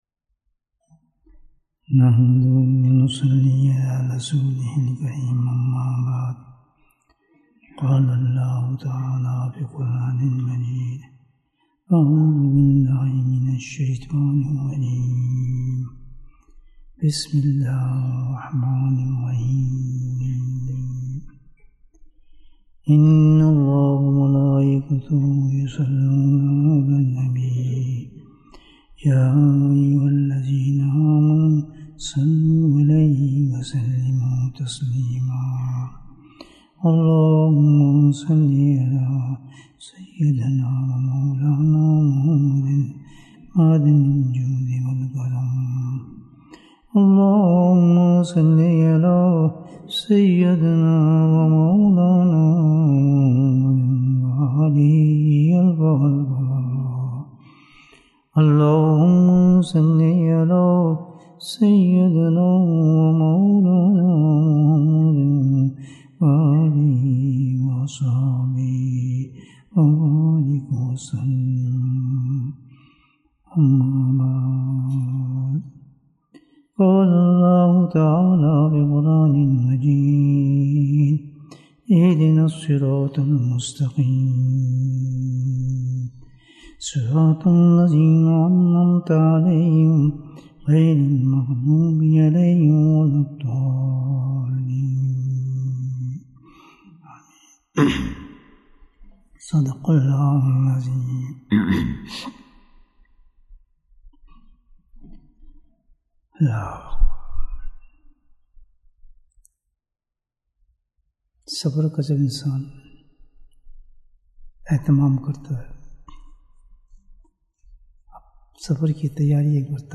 کیا آپ جانتے ہیں آخرت کا پاسپورٹ کو ن سا ہے؟ Bayan, 56 minutes22nd September, 2022